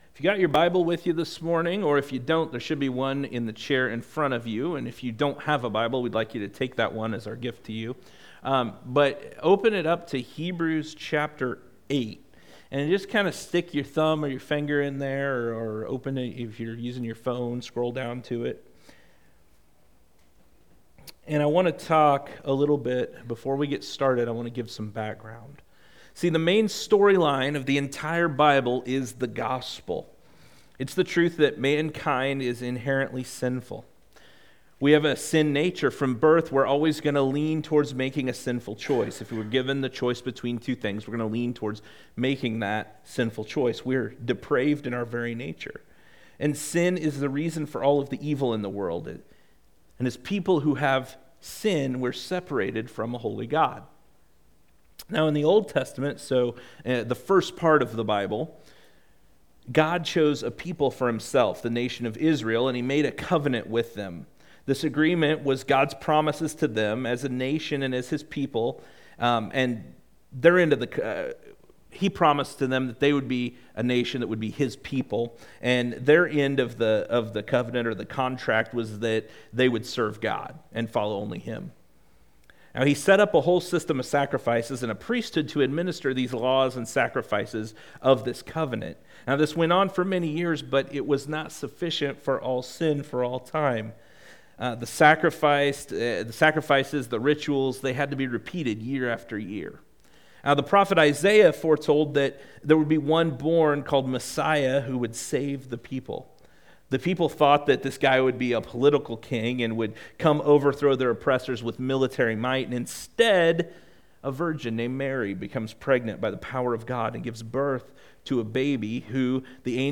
Weekly messages/sermons from GFC Ashton.